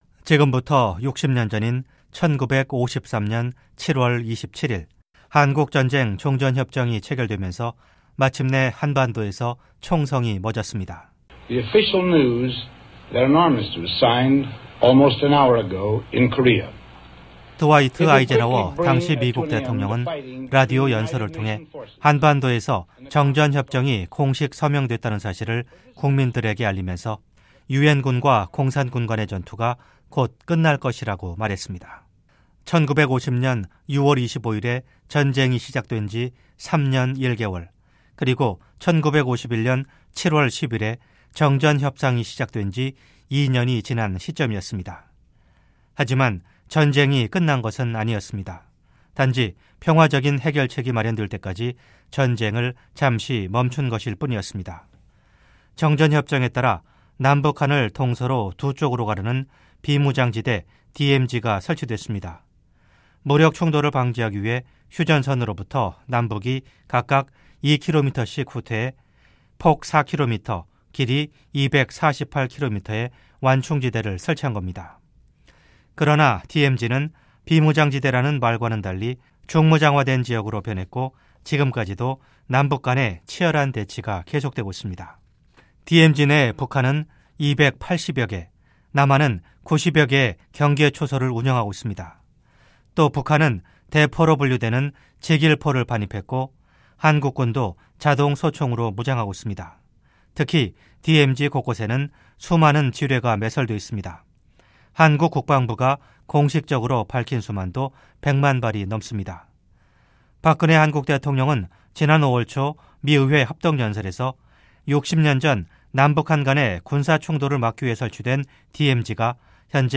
저희 `VOA'는 60주년을 맞은 정전협정을 다섯 차례로 나눠 살펴보는 기획보도를 준비했습니다. 오늘은 첫 번째 순서로, 정전 60년의 현황과 의미를 살펴보겠습니다.